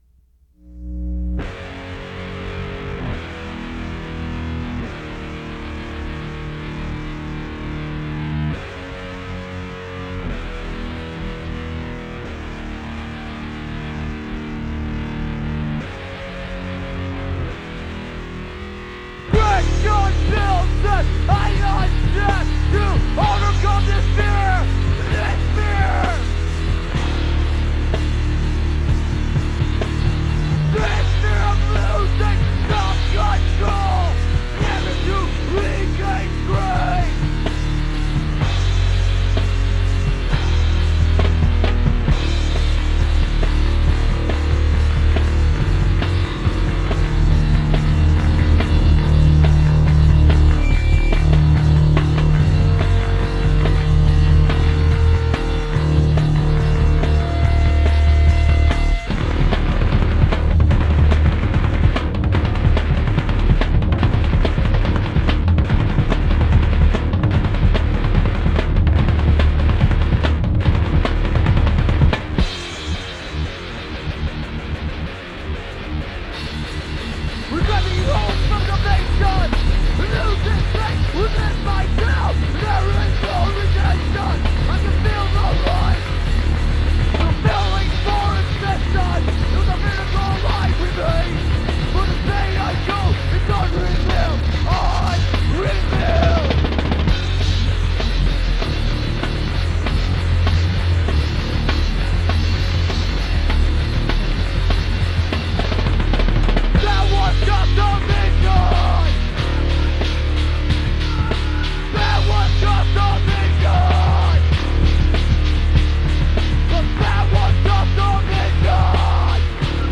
Vocals
Drums
Bass
Guitar
Hardcore , Straight Edge